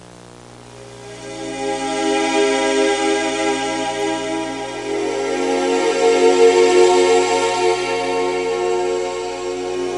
Generic Intro Sound Effect
Download a high-quality generic intro sound effect.
generic-intro.mp3